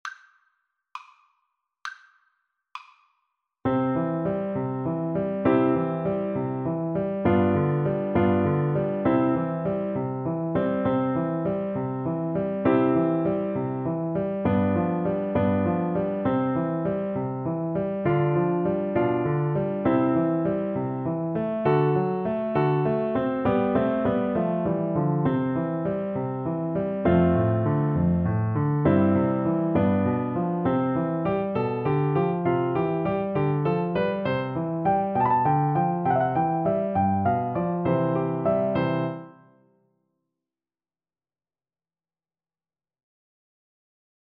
C major (Sounding Pitch) (View more C major Music for Cello )
~ = 100 Fršhlich
6/8 (View more 6/8 Music)
Classical (View more Classical Cello Music)